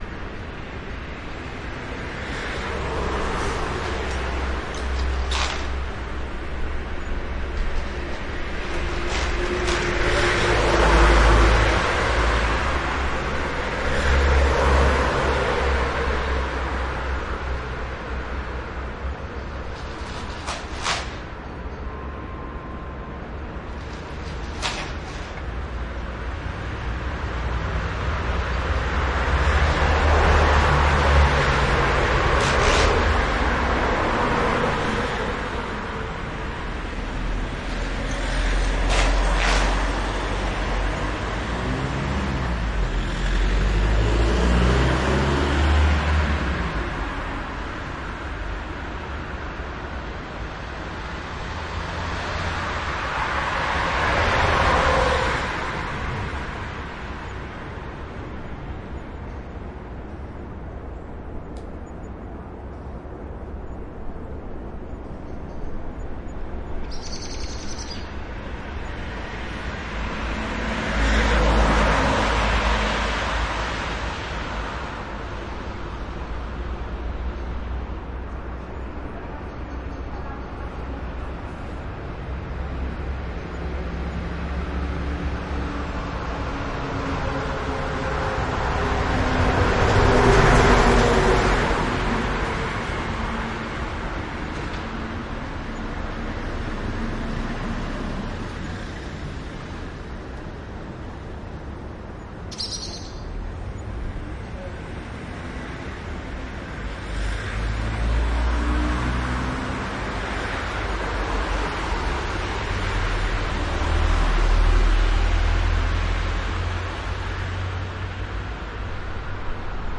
城市世界 " 交通运输 Verkehr elektrotram City Car
描述：我晚上22点录制。